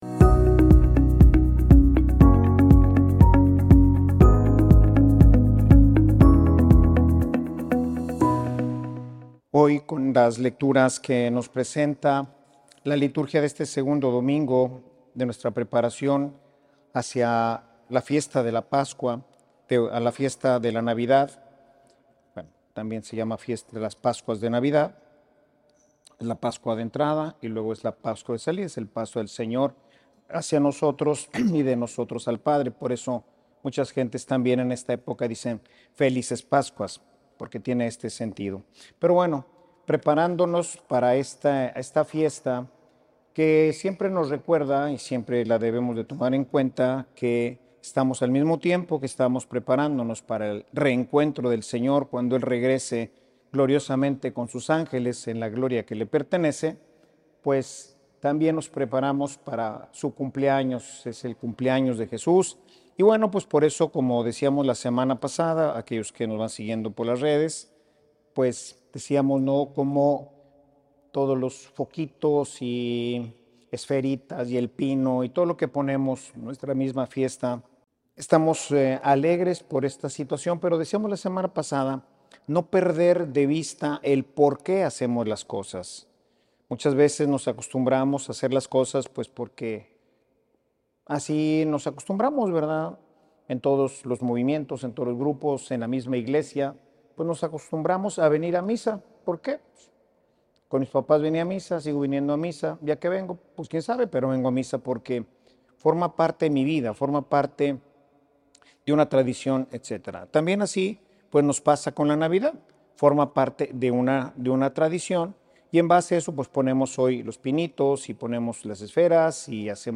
Escucha este audio aquí 0:00 0:00 0.5x 0.75x Normal 1.25x 1.5x Mira el video de esta homilía Ver en YouTube ¿Qué hacemos nosotros cuando es nuestro cumpleaños o el de alguno de nuestros seres queridos?
Homilia_De_que_esta_llena_la_navidad.mp3